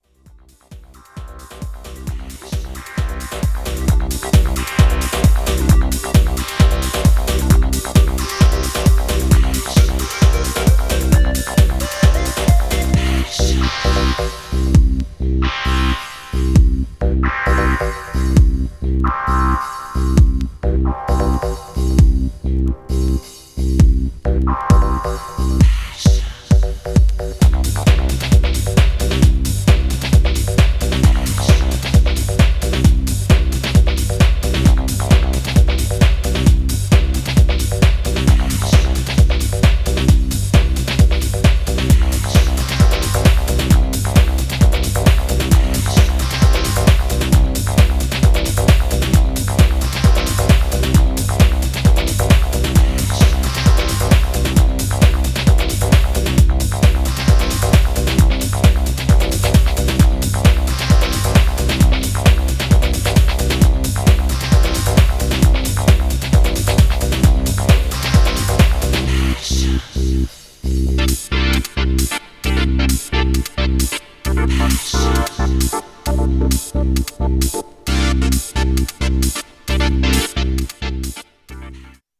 Styl: Progressive, House, Techno, Trance